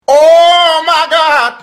Oh my god meme Oh my god meme sound effect free mp3 download instant sound button online free mp3 download sound
Oh-my-god-meme.mp3